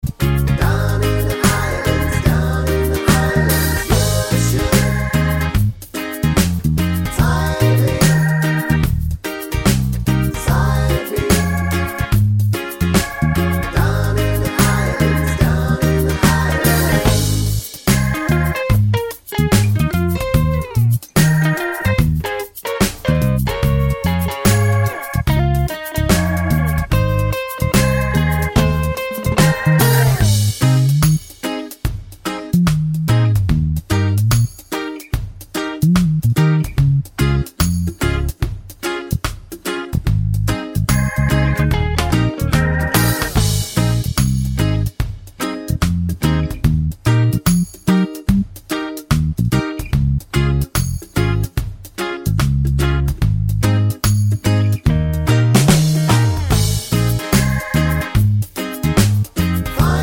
no Backing Vocals Reggae 3:39 Buy £1.50